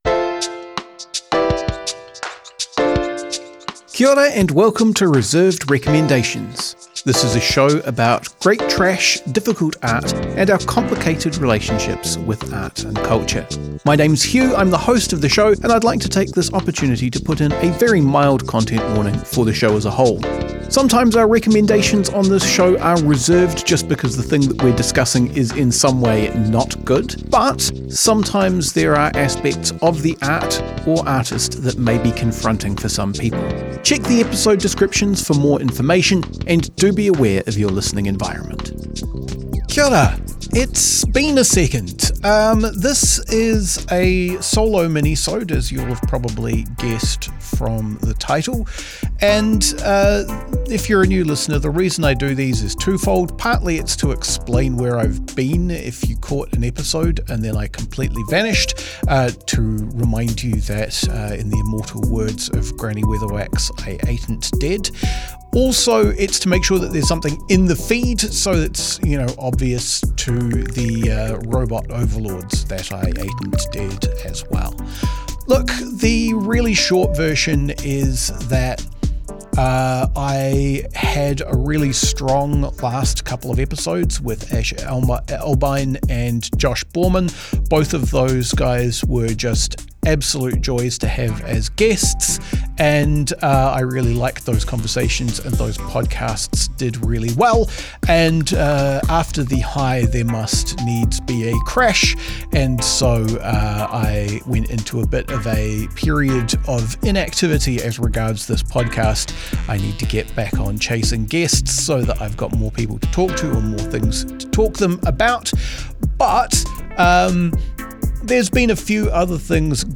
I take a solo minisode to explain what I've been up to for a month and a half, and how Winston Peters Has Always Been Like This.